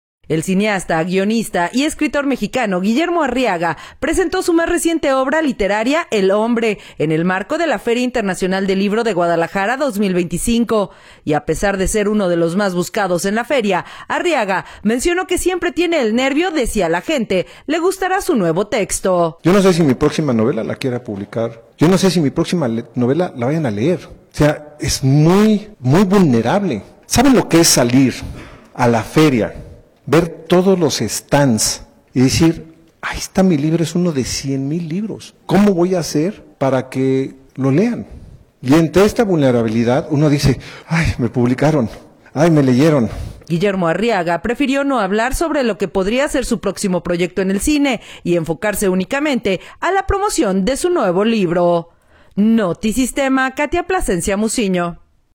El cineasta, guionista y escritor mexicano Guillermo Arriaga presentó su más reciente obra literaria, “El Hombre”, en el marco de la Feria Internacional del Libro de Guadalajara 2025.